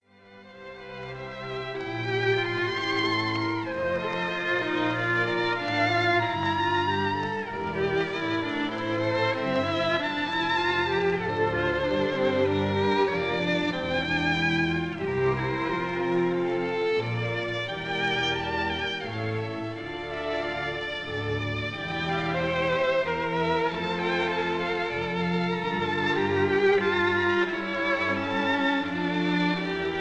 historic 1932 recording